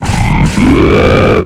Cri d'Excavarenne dans Pokémon X et Y.